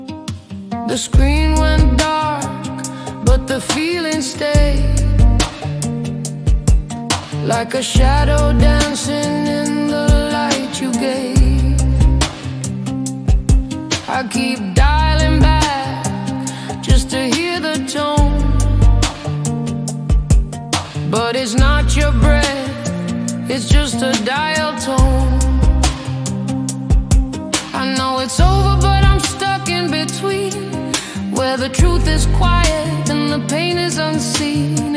Жанр: Регги